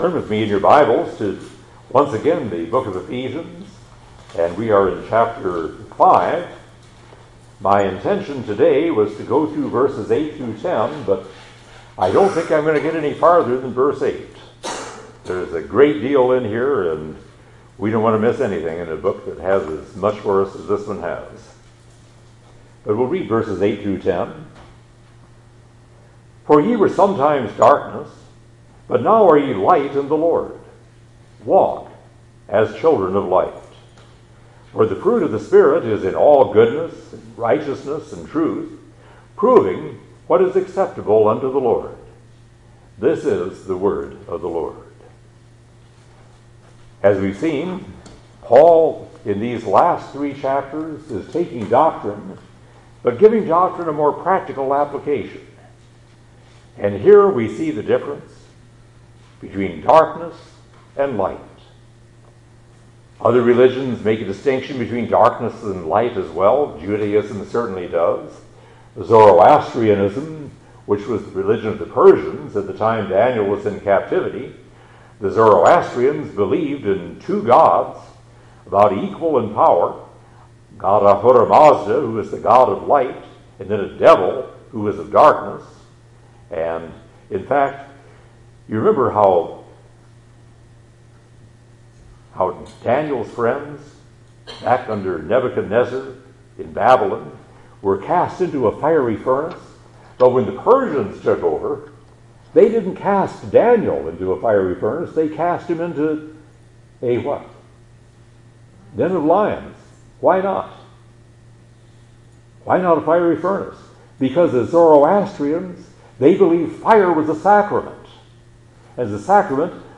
2024 Preacher